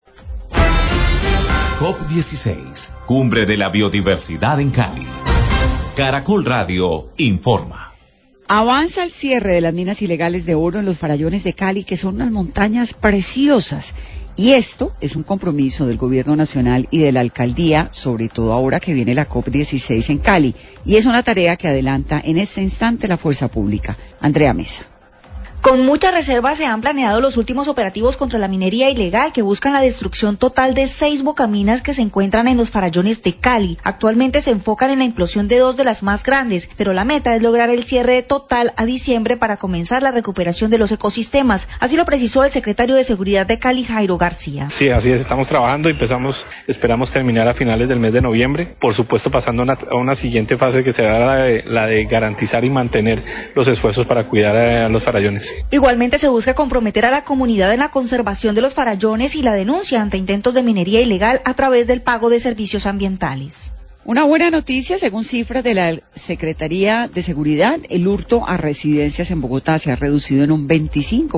Radio
Entervista de Vanessa de la Torre con Jairo Garcia, Secretario de Seguridad de Cali, quien habla del avance de los operativos de destrucción de minas ilegales de oro en el Parque Nacional Natural Los Farallones. Se estima que para este próximo mes de dciiembre se hayan erradicado todas estas bocaminas en este resrva natural.